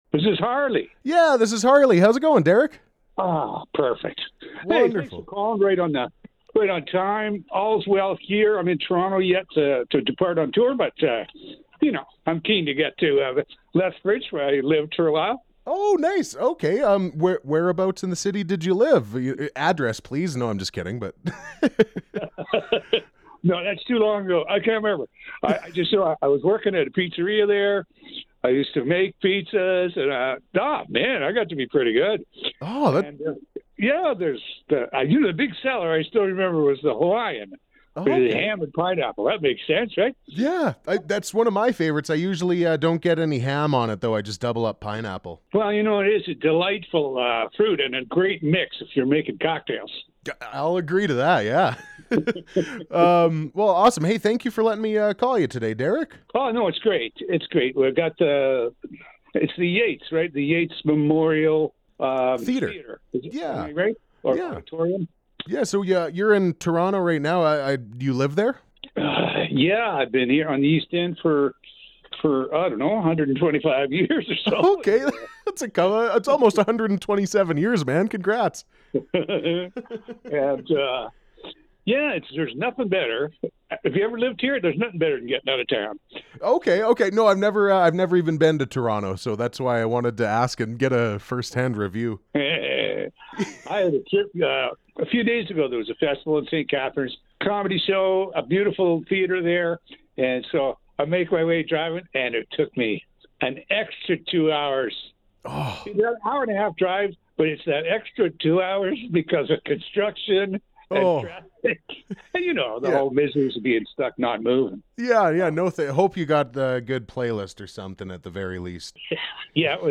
derek-edwards-interview-full-complete.mp3